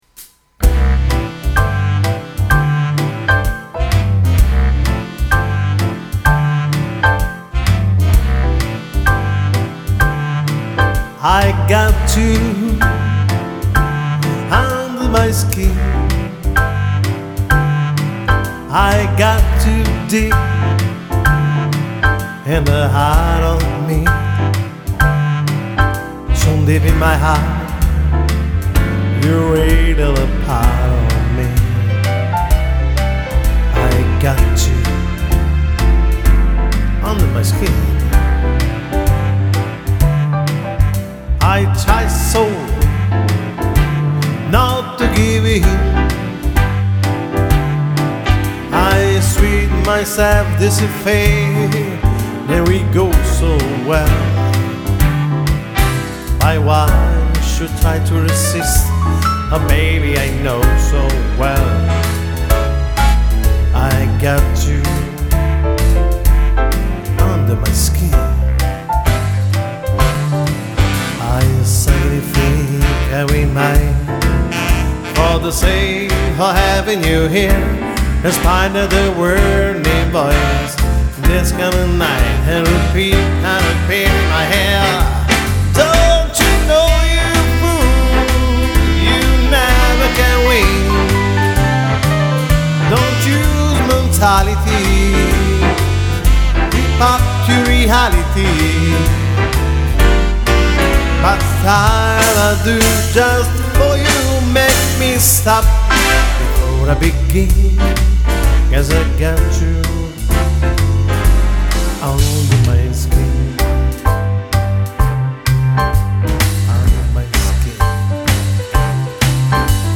pianista cantante